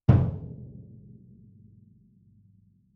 bdrum_muted_ff_rr1.mp3